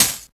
51 HAT 2.wav